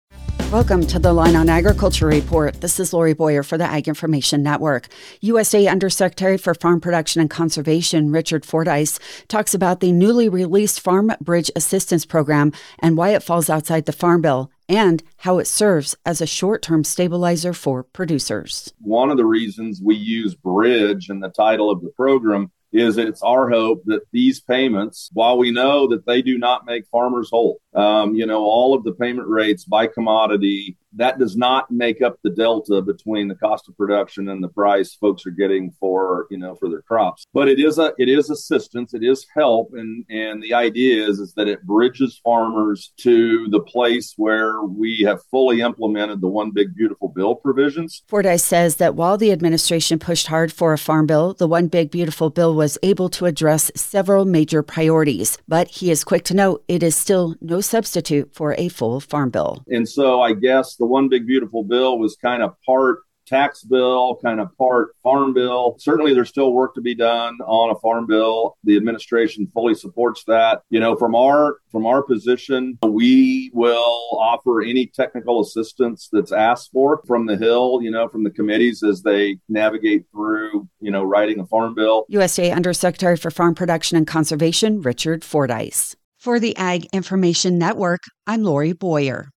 USDA Undersecretary for farm production and conservation, Richard Fordyce, talks about the newly released Farm Bridge Assistance Program and why it falls outside the farm bill, and how it serves as a short-term stabilizer for producers.